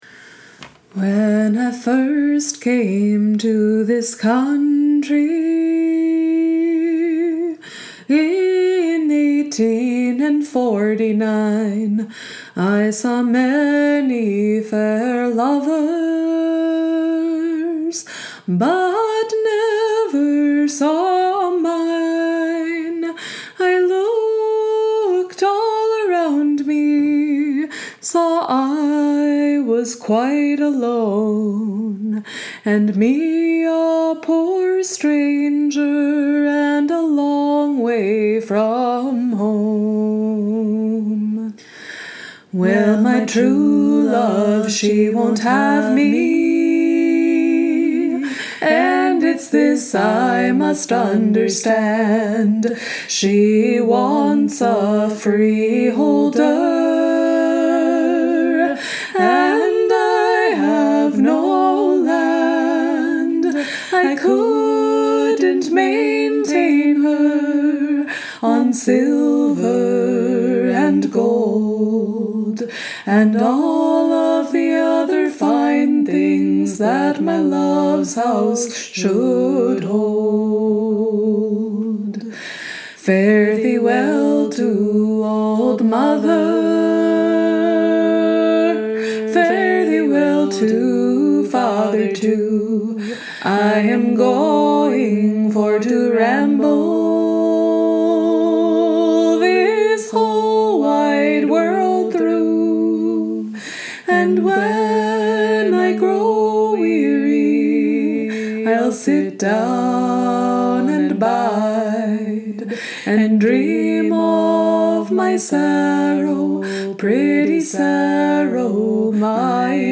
Pretty Saro, trad. Appalachian